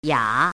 “哑”读音
哑字注音：ㄧㄚˇ/ㄧㄚ
国际音标：jɑ˨˩˦;/jɑ˥
yǎ.mp3